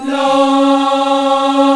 AFROLA C#4-R.wav